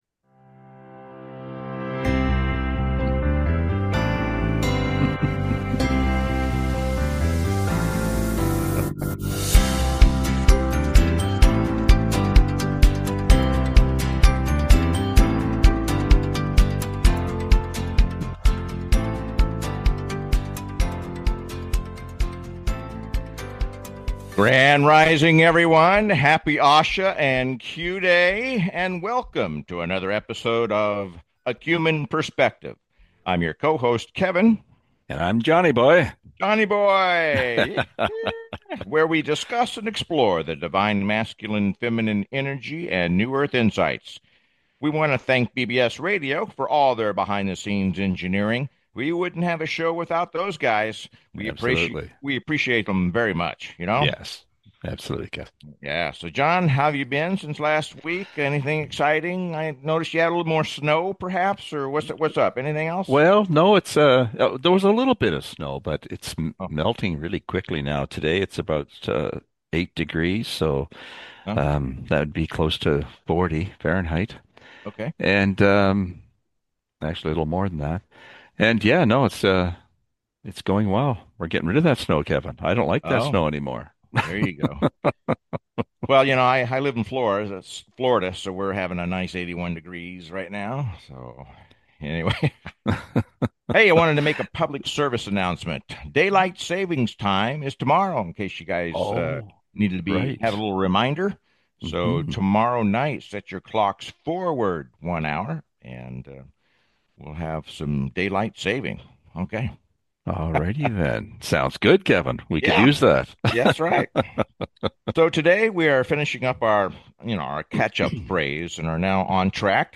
Talk Show Episode, Audio Podcast, A Qmen Perspective and A Human Perspective: Navigating the Great Awakening and Operation Epic Fury on , show guests , about A Human Perspective,Navigating the Great Awakening,Operation Epic Fury,Divine Masculine/Feminine Energy,New Earth Insights,Spiritual Resilience,standing ovation,Middle East Transformation,Unveiling the Global Syndicate,Institutional Infiltration, categorized as Comedy,Education,Health & Lifestyle,Paranormal,Philosophy,Mental Health,Self Help,Society and Culture,Spiritual